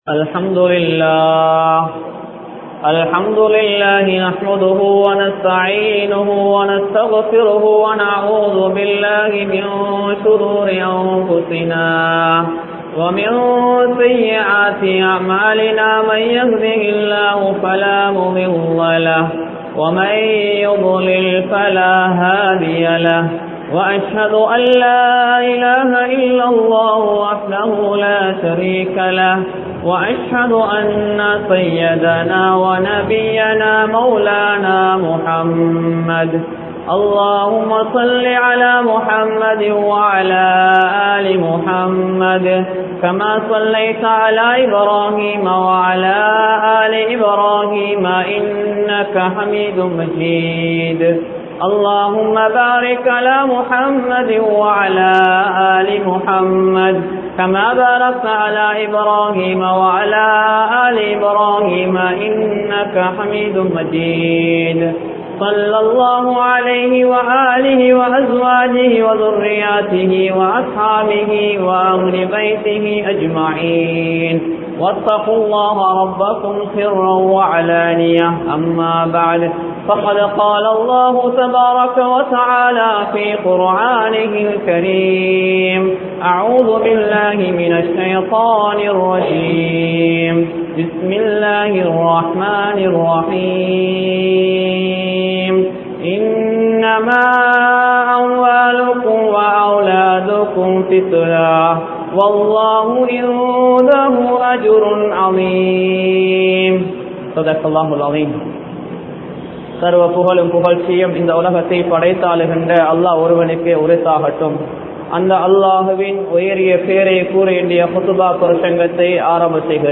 Pettroarhalin Poruppuhal (பெற்றோர்களின் பொறுப்புகள்) | Audio Bayans | All Ceylon Muslim Youth Community | Addalaichenai
Colombo 10, Maligawatttha, Grand Jumua Masjidh